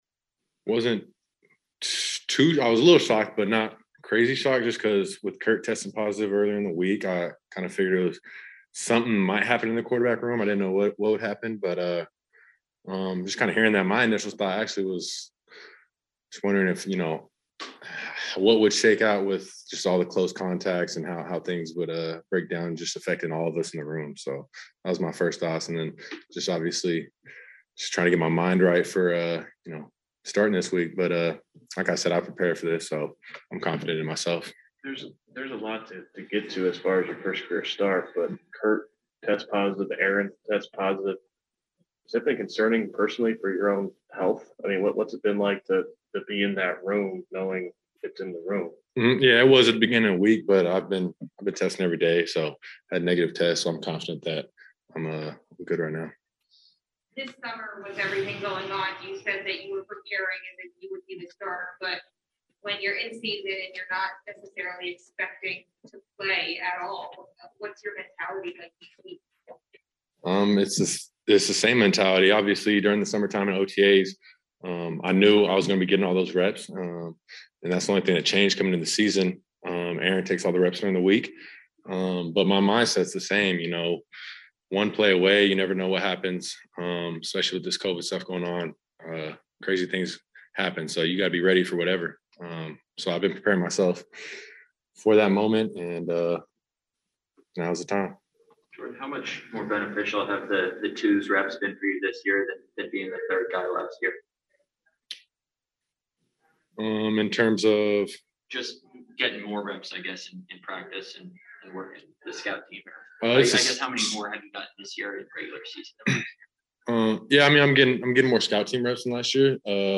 After Friday’s practice, reporters had a chance to visit with Love for the first time since August 5.   He fielded questions about his reaction to the news of Rodgers’ positive test, what this work week has been like and how big the butterflies might be come Sunday in Kansas City.